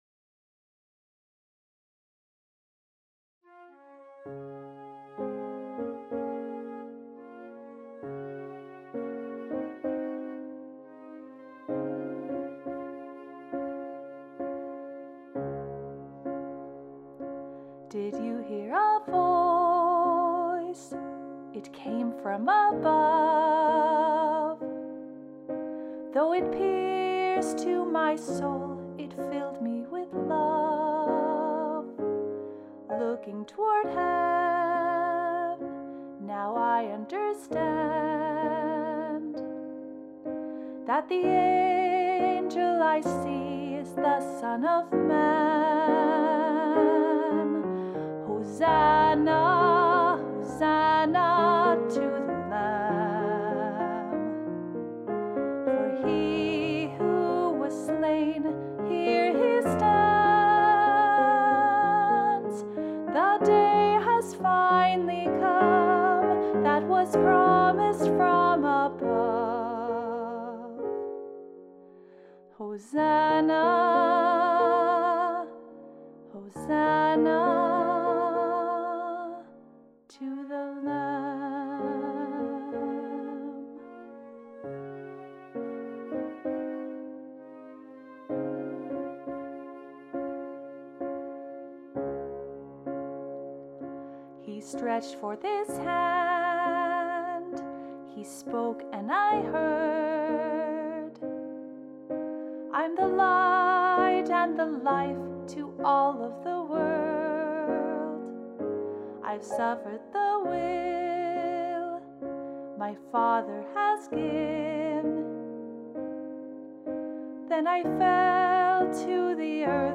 Vocal Solo
Medium Voice/Low Voice
Flute Optional Obbligato/Flute Accompaniment